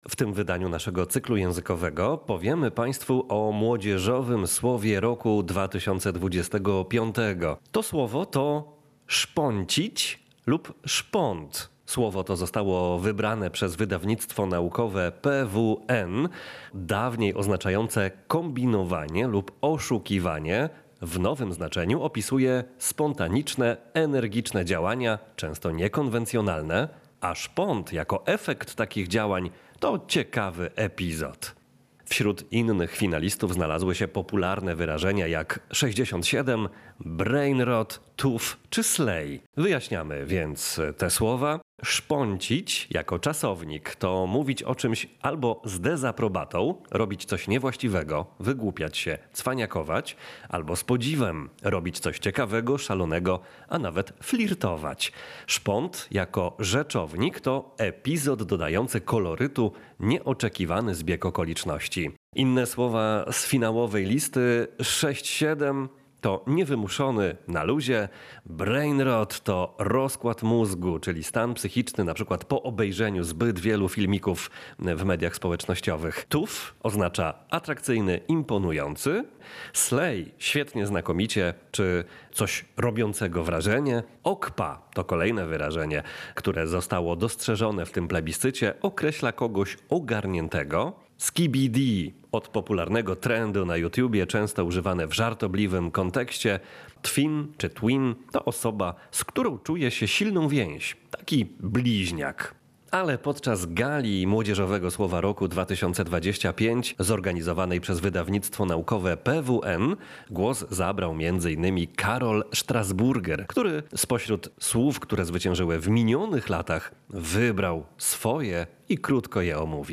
Posłuchaj relacji z gali ogłoszenia zwycięzców plebiscytu